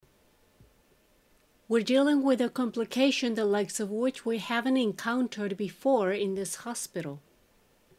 ナチュラルスピード：